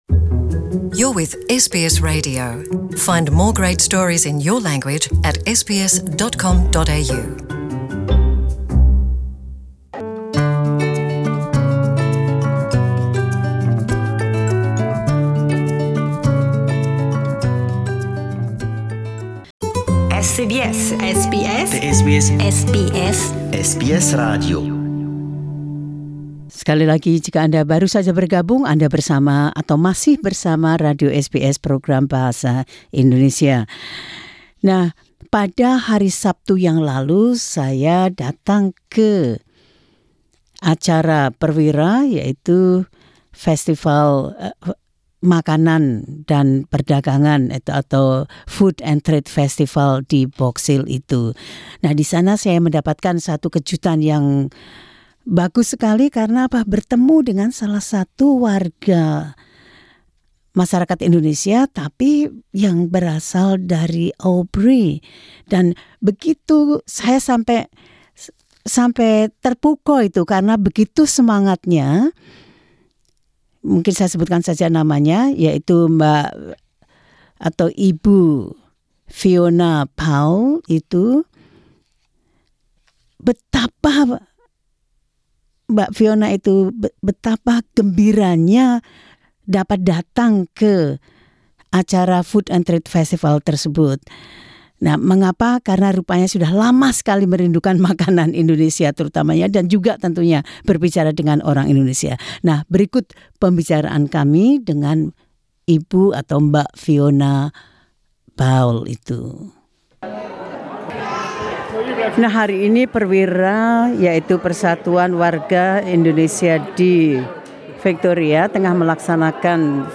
at 2019 PERWIRA Food & Tarde Festival at Box Hill Town Hall, Melbourne, 26 Oct.